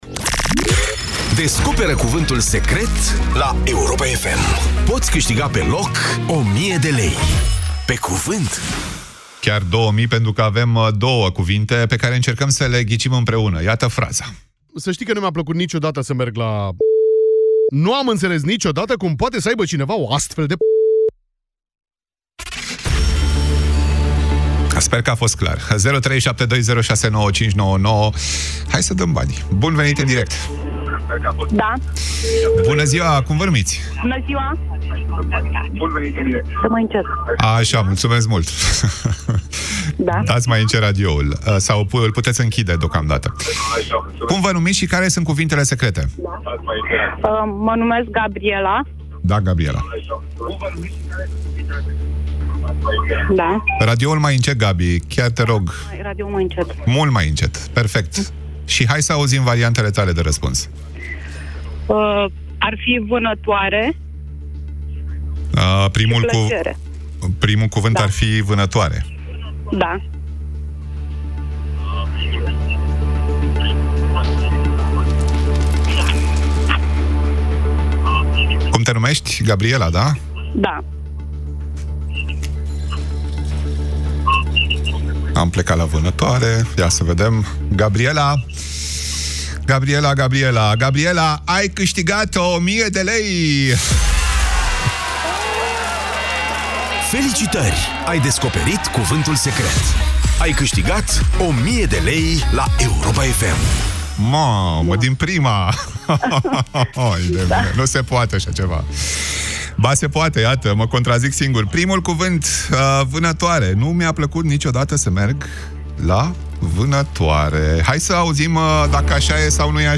Noua fraza propusă la Europa FM are două cuvinte secrete de descoperit. Primul Cuvânt Secret a fost identificat corect, în direct la Europa FM, în emisiunea Drum cu Prioritate.